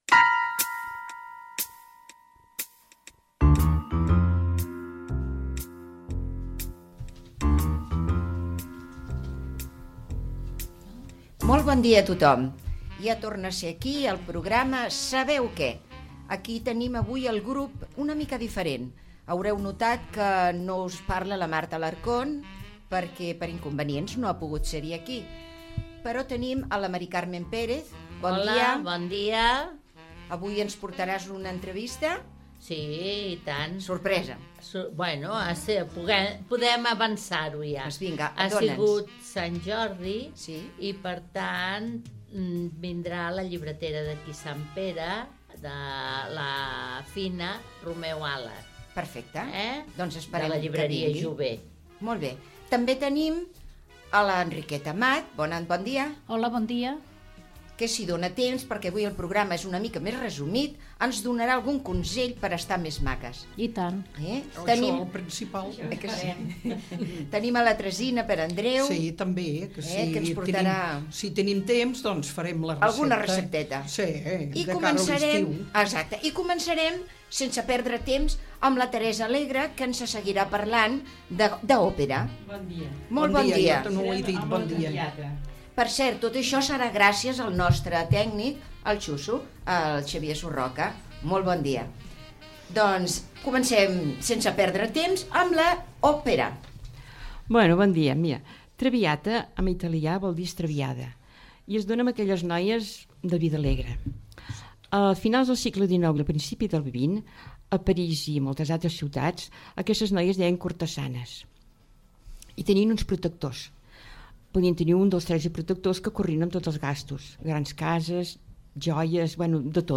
Sintonia del programa, presentació, avenç de continguts, l'òpera "La traviata" (en català L'esgarriada) de Giuseppe Verdi
Entreteniment
FM